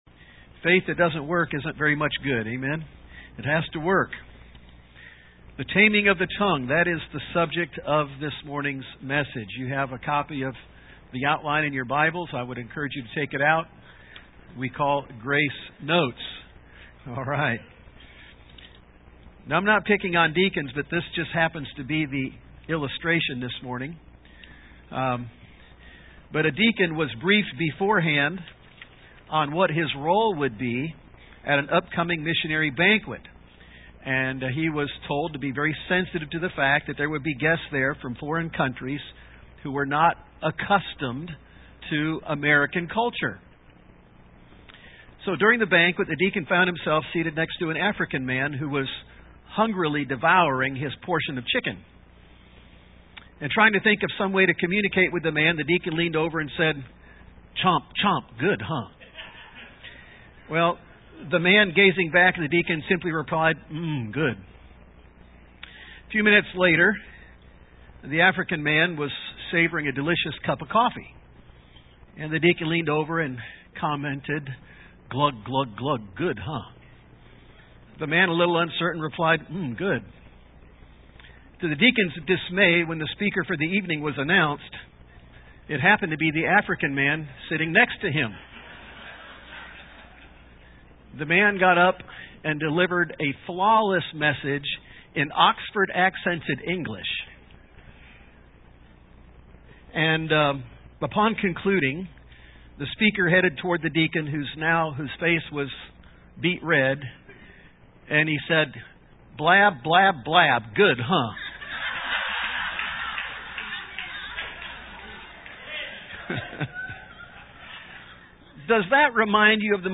Service Type: AM